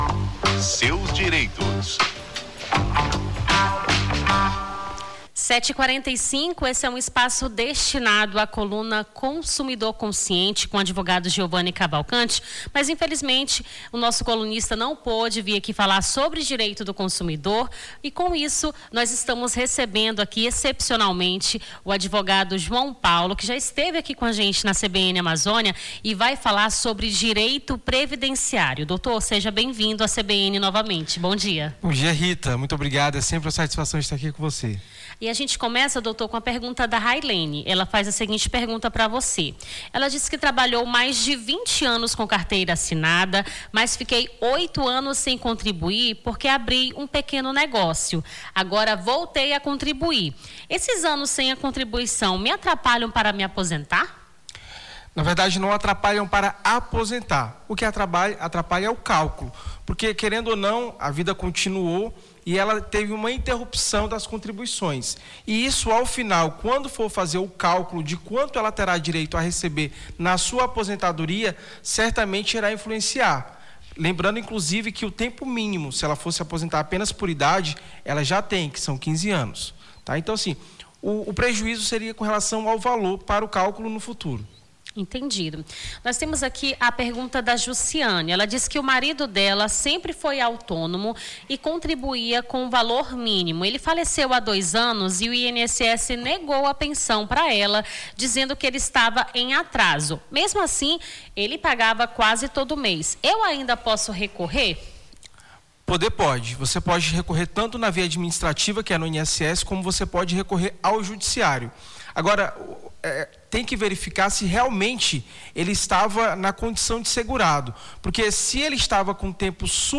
Seus Direitos: advogado tira dúvidas sobre direito previdenciário